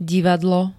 Zvukové nahrávky niektorých slov
rac3-divadlo.ogg